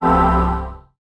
recovery.mp3